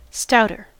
Ääntäminen
Ääntäminen US Haettu sana löytyi näillä lähdekielillä: englanti Käännöksiä ei löytynyt valitulle kohdekielelle. Stouter on sanan stout komparatiivi.